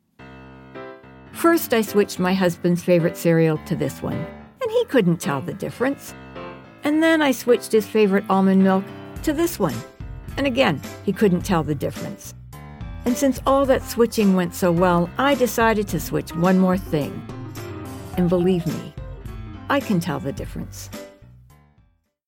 Female
English (North American)
Adult (30-50), Older Sound (50+)
Radio / TV Imaging
Comedy, Conversational, Natural
0620The_Voice_Realm_Comedy_demo.mp3